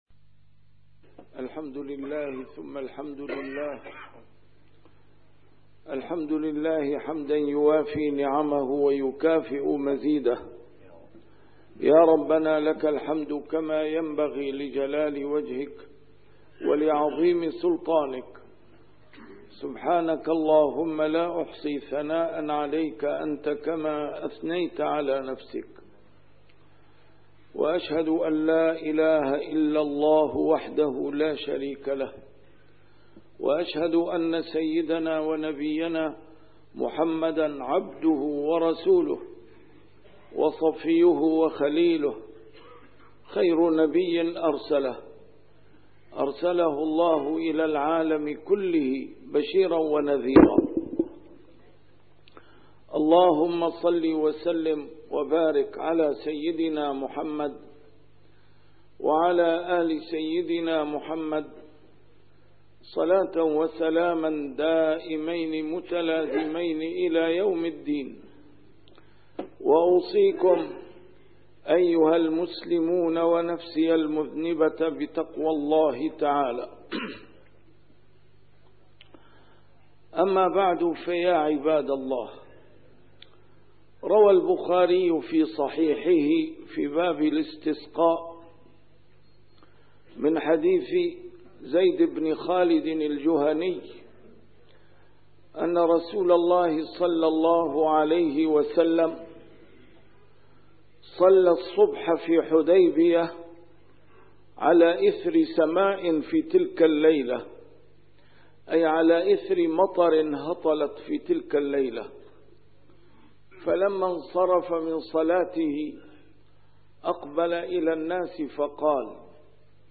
A MARTYR SCHOLAR: IMAM MUHAMMAD SAEED RAMADAN AL-BOUTI - الخطب - بل .. (مطرنا بفضل الله وبرحمته)